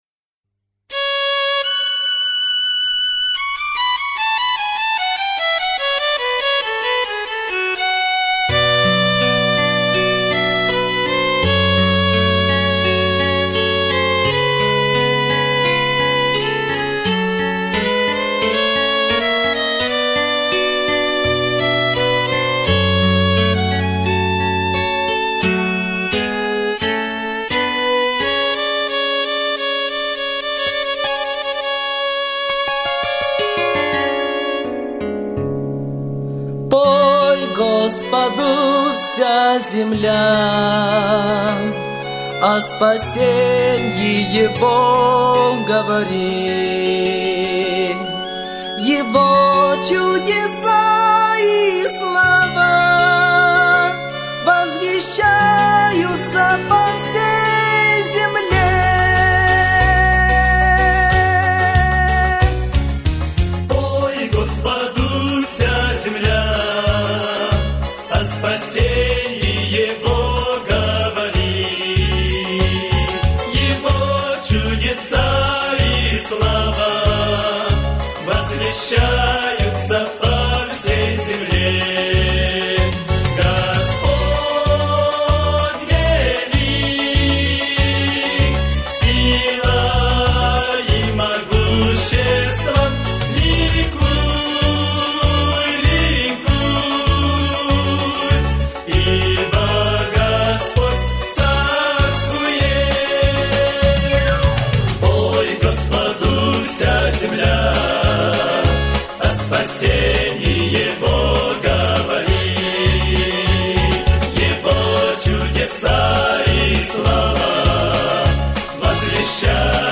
562 просмотра 873 прослушивания 118 скачиваний BPM: 77